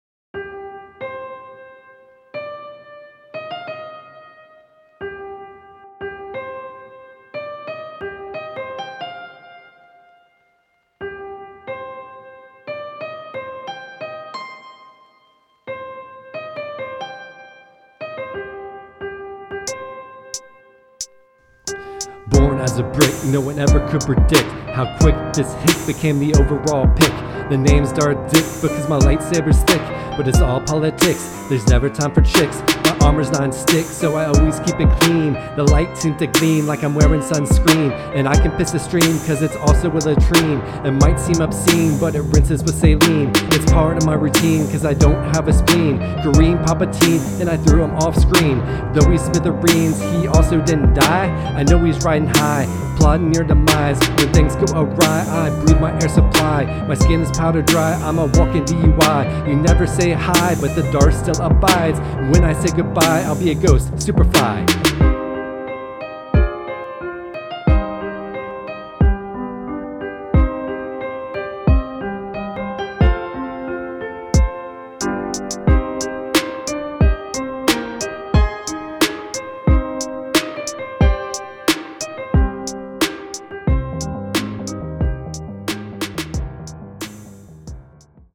Rap from Episode 99: Lego Star Wars: The Skywalker Saga – Press any Button
ep99-lego-starwars-rap.mp3